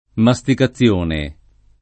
masticazione [ ma S tika ZZL1 ne ] s. f.